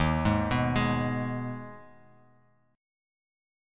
D#sus4#5 chord